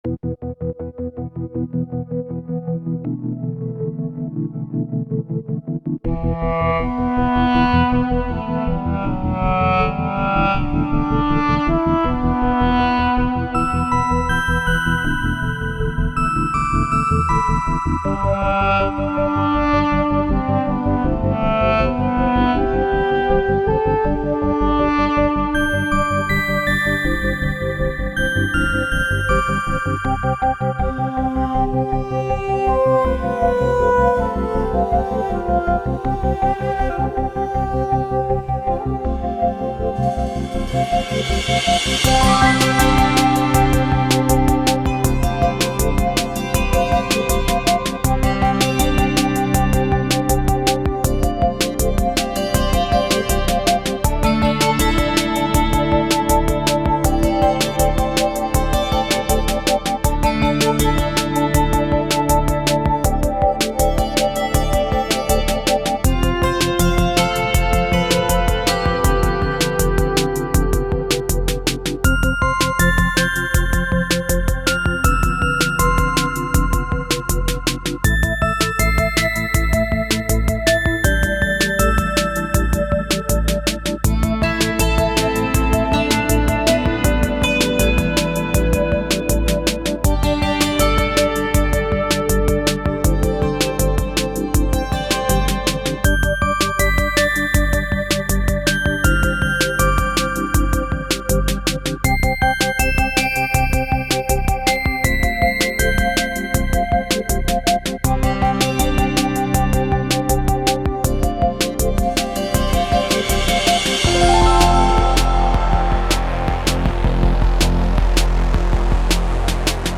με chillout διάθεση